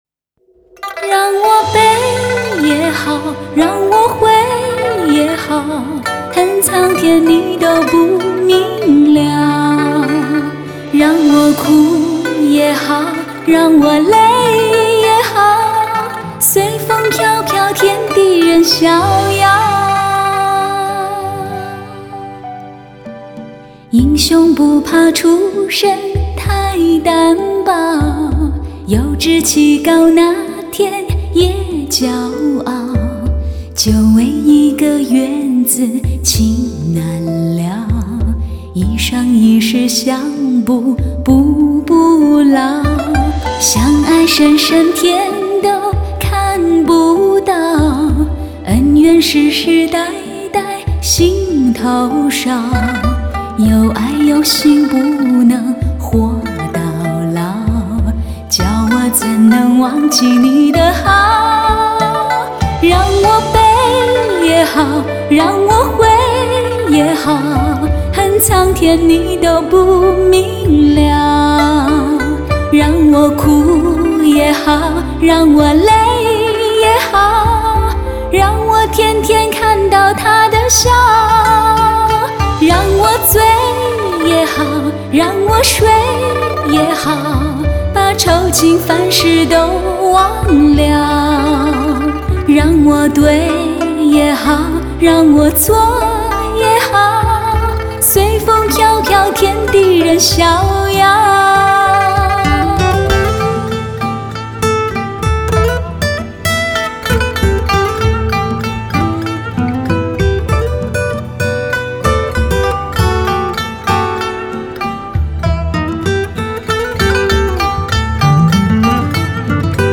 Жанр: Cinese Pop